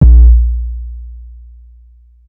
Waka KICK Edited (25).wav